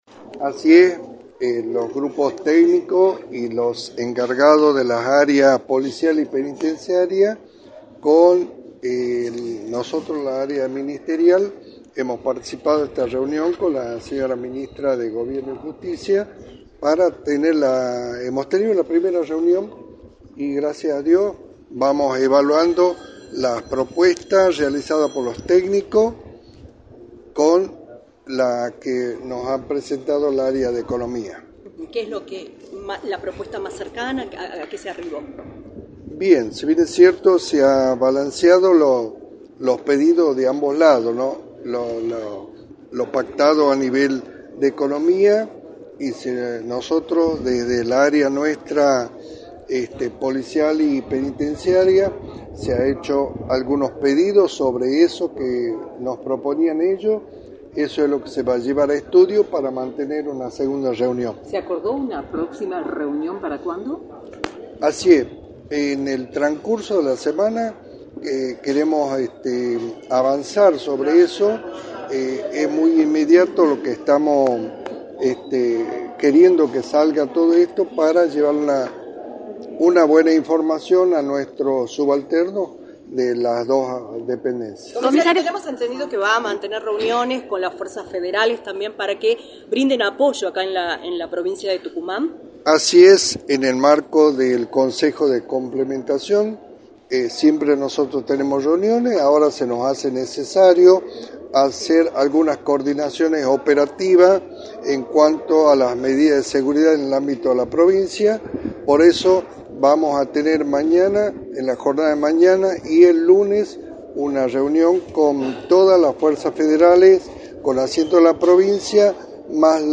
“Hace una semana que venimos trabajando en esta tarea, la Jefatura de Policía ha reforzado los operativos, tal es así que mañana vamos a realizar un operativo mayúsculo en toda la extensión territorial, tenemos que tomar previsiones, se hace necesario mantener el diálogo para mostrar a la sociedad el trabajo en conjunto” señaló Luís Ibáñez en entrevista para “La Mañana del Plata”, por la 93.9.